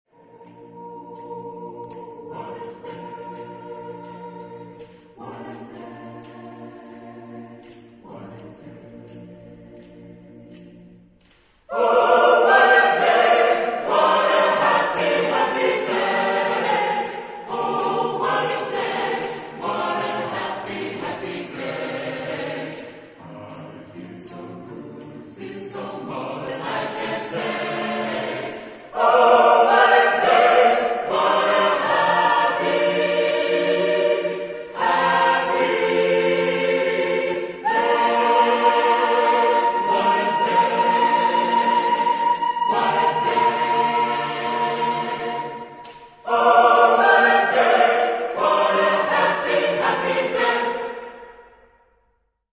Genre-Style-Form: Secular ; African-American
Mood of the piece: energetic
Type of Choir: SATB  (4 mixed voices )